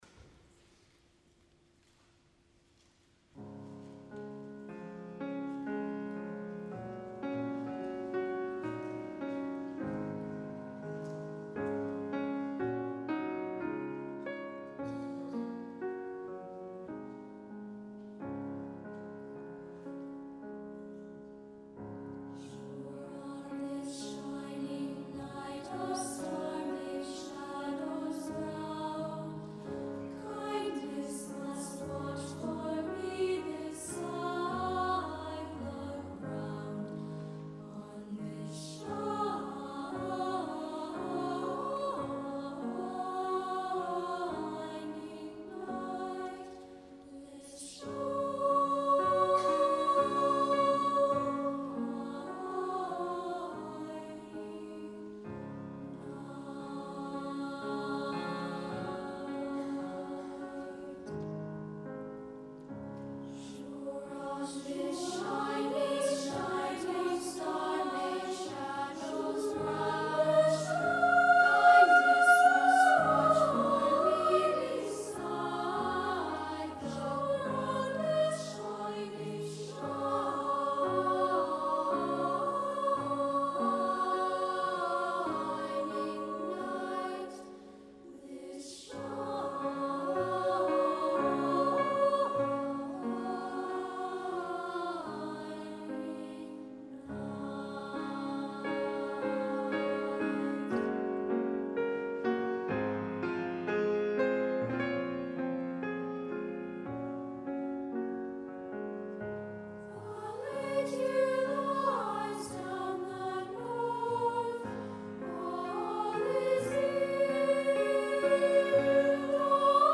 Ahir, tal i com ja es va anunciar fa dies a IFL, va tenir lloc a l’auditori AXA de l’Illa Diagonal el darrer concert de la temporada del Cor Vivaldi, el concert d’estiu que enguany comptava amb dos cors invitats arribats dels Estats Units (Piedmont East Bay Children’s Choir) i Dinamarca (Copenhagen girls choir) per interpretar a la segona part el Te Deum d’Albert Guinovart, mentre que a la primera, cadascuna de les formacions va fer un tast del seu repertori, sent el Vivaldi qui va compartir amb cadascun dels cors invitats, una obra en conjunt.
Piedmont East Bay Children’s Choir de San Francisco